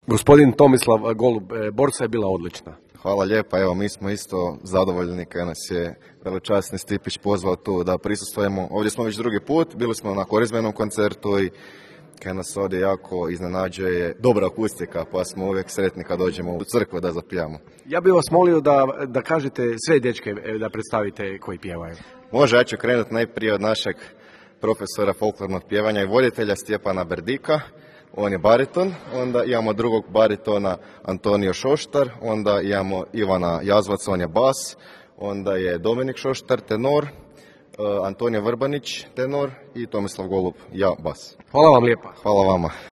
Svečani Božićni koncert održan je sinoć u Župnoj crkvi Preobraženja Gospodnjega u Jastrebarskom.
Osim draganićke muške vokalne grupe Borsa, jučer su nastupili i Gradsko pjevačko društvo Javor, Župski zbor crkve svetog Nikole u Jastrebarskom, Vokalni ansambl Ad Hoc, KUD Cvijet Cvetković i po prvi puta Zbor župe Preobraženja gospodnjega.